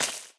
block_stone_destroy2.ogg